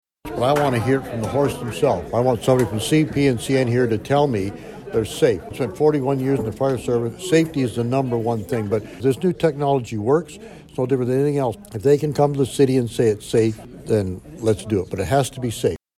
Councillor Dave McCue tells Quinte News he’s in favour of a no whistle order, but only if it’s safe and he wants to hear directly from CN representatives about that.